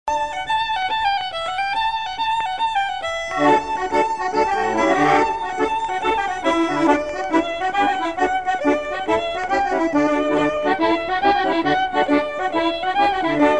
Couplets à danser
Résumé instrumental
danse : branle : courante, maraîchine
Pièce musicale inédite